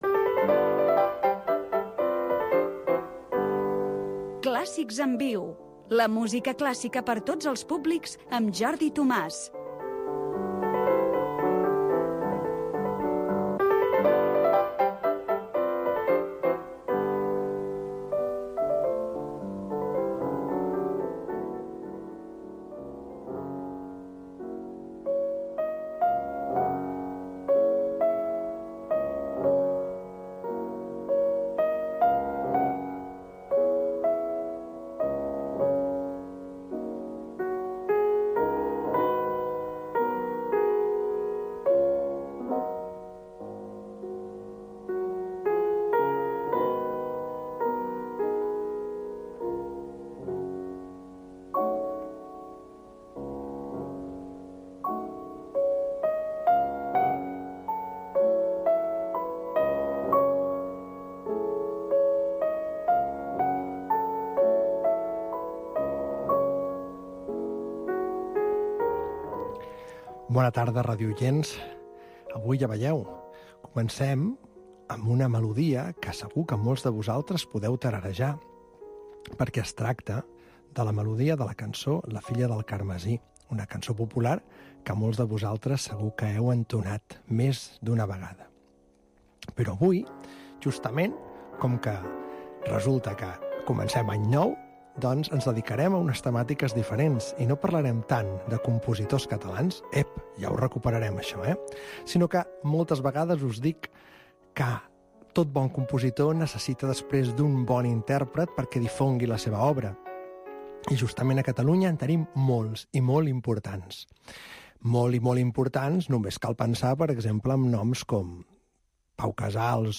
Clàssics en viu, programa de música clàssica per tots els públics.